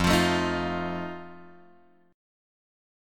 F7 chord {1 3 1 2 4 1} chord